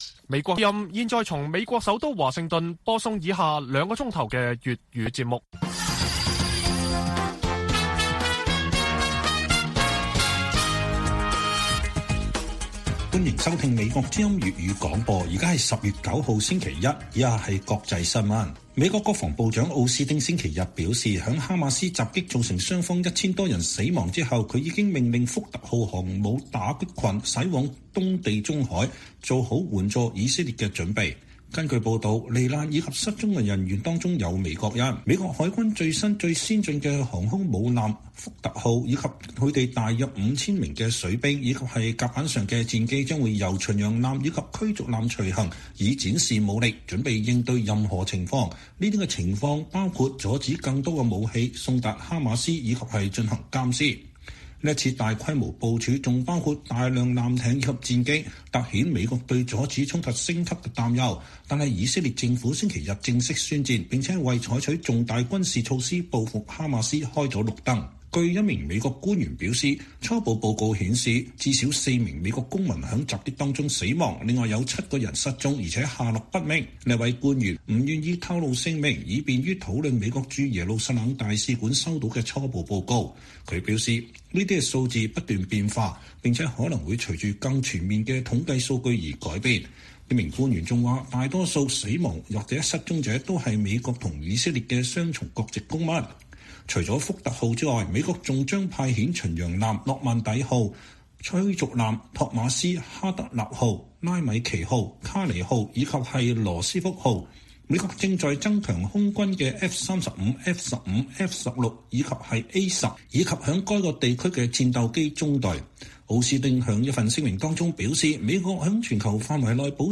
粵語新聞 晚上9-10點: 美國派遣航母戰鬥群前往地中海支持以色列